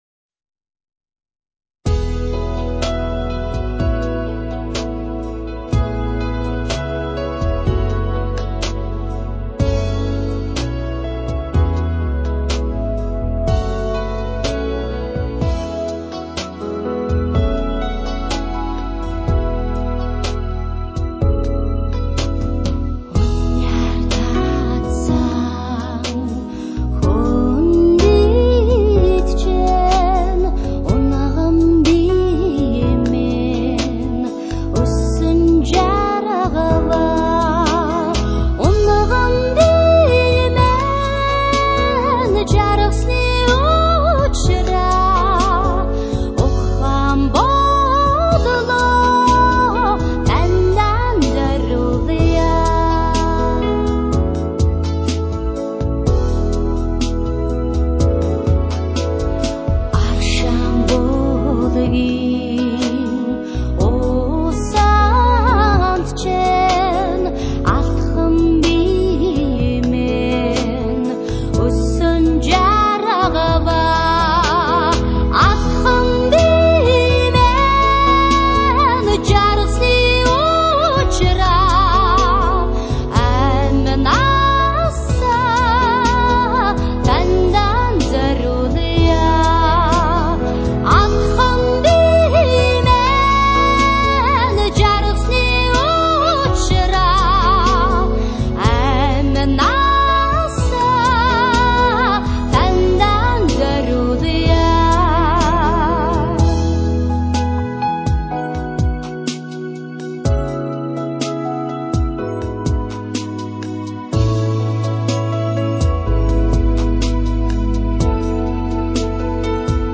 这是张鲜有的纯蒙古风味的原生态音乐之女生CD。